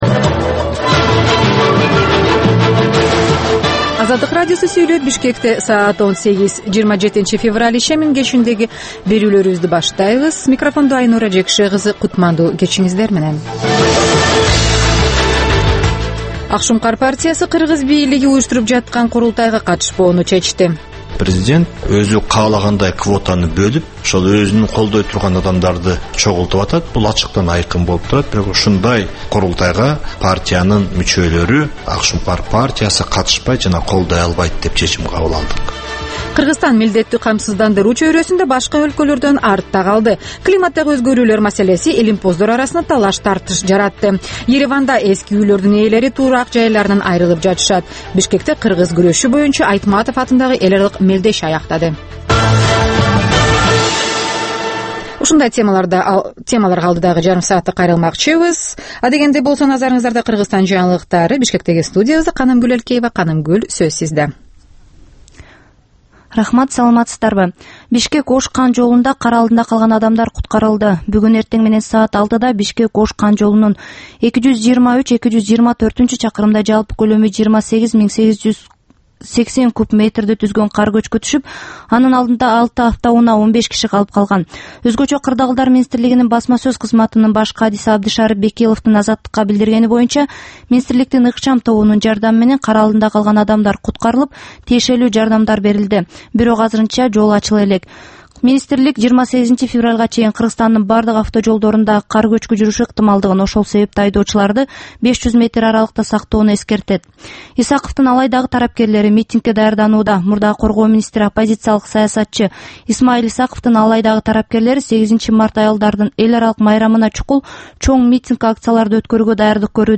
"Азаттык үналгысынын" бул кечки алгачкы берүүсү жергиликтүү жана эл аралык кабарлардан, репортаж, маек, баян жана башка берүүлөрдөн турат. Бул үналгы берүү ар күнү Бишкек убактысы боюнча саат 18:00ден 18:30га чейин обого түз чыгат.